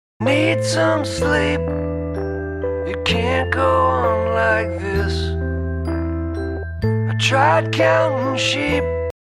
형집행 브금입니다